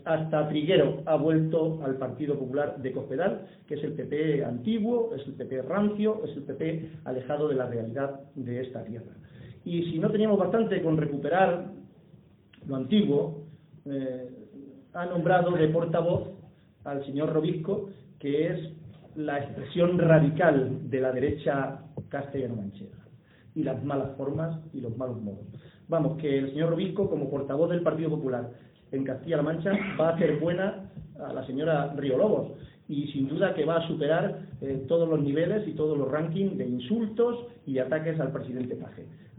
EN LA ASAMBLEA ABIERTA DEL PSOE DE SOCUÉLLAMOS
Cortes de audio de la rueda de prensa